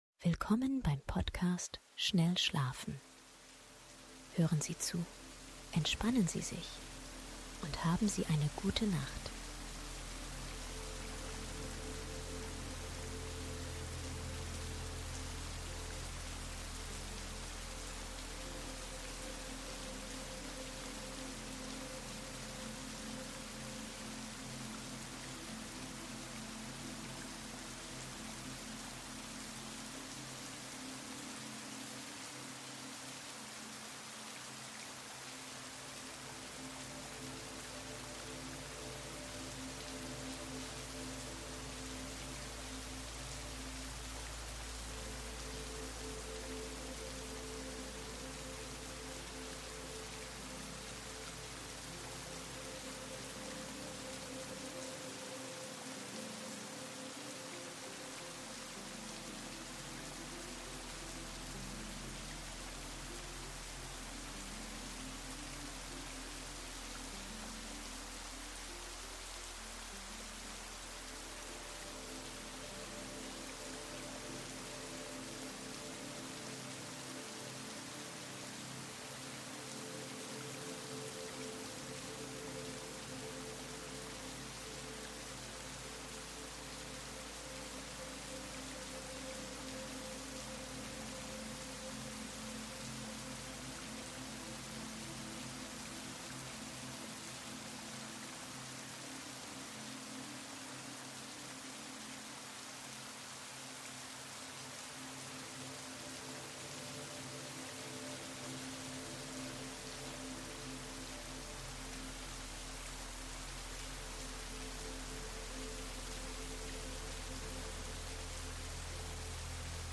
Perfekte Mischung aus REGEN und MUSIK für tiefen SCHLAF